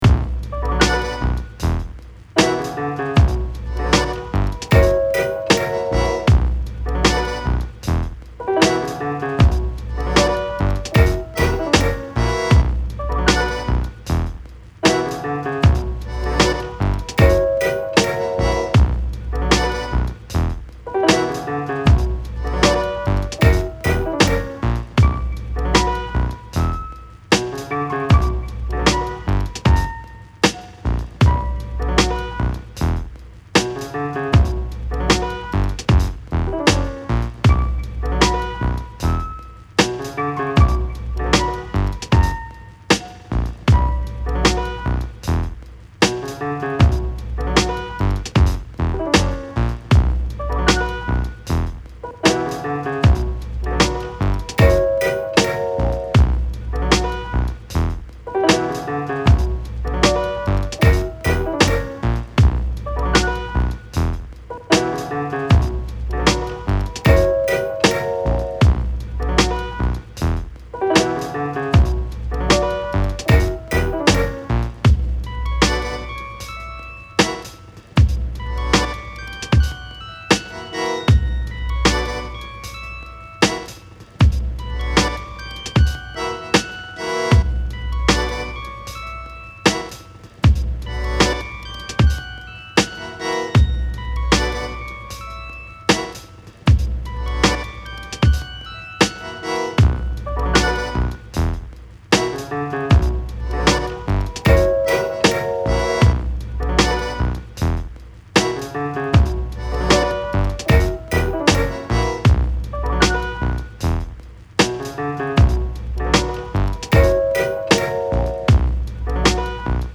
Weird downtempo beat with peculiar piano theme.